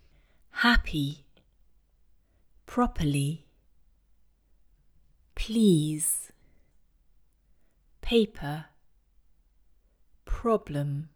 While we want to activate our vocal folds for the /b/ sound, the English /p/ sound is unvoiced and requires more aspiration (a puff of air) when released.